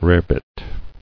[rare·bit]